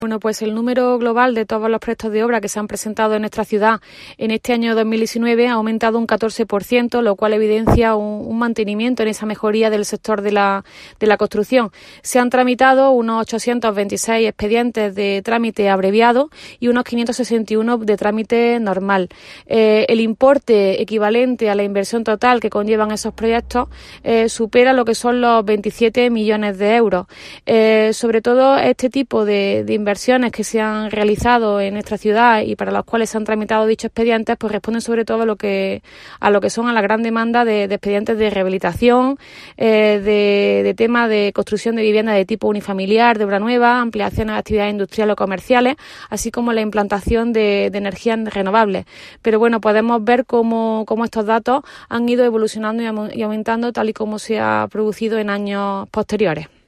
La teniente de alcalde delegada de Urbanismo, Teresa Molina, confirma que nuestra ciudad volvió a registrar en el conjunto del pasado año 2019 una buena salud del sector de la construcción como así lo acreditan los datos relativos al número de proyectos de obra presentados para los que se solicitaron licencia de obra en el Ayuntamiento.
Cortes de voz